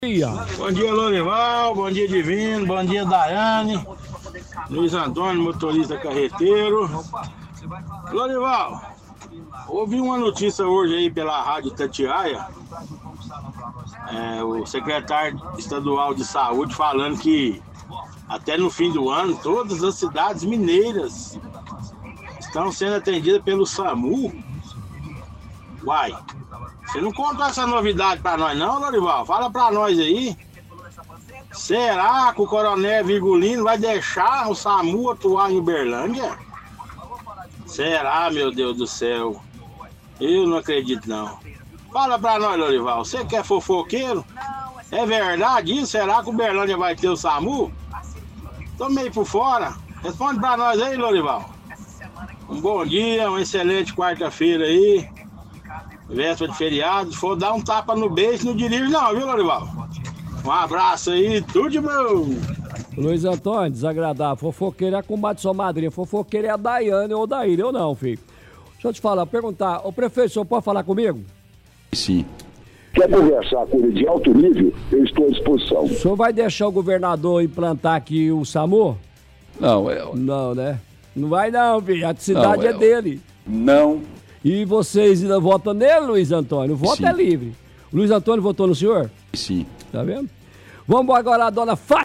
– Ouvinte fala sobre possibilidade de ser implementado o SAMU em Uberlândia.